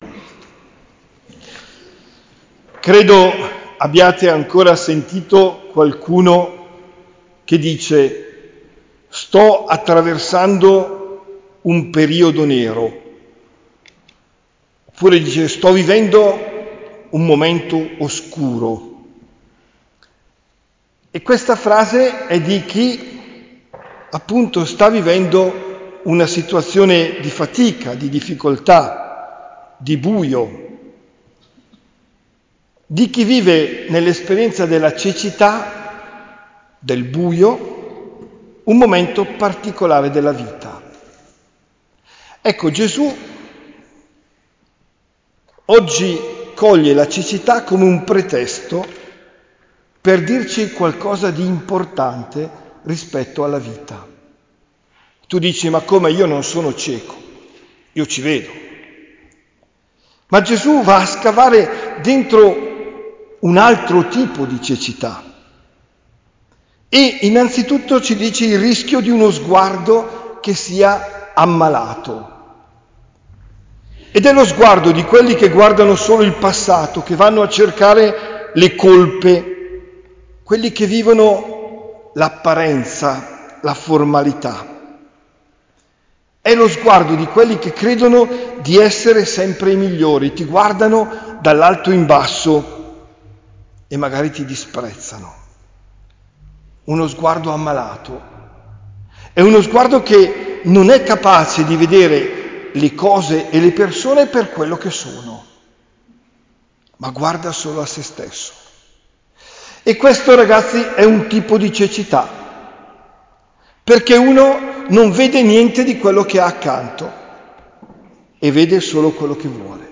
OMELIA DEL 19 MARZO 2023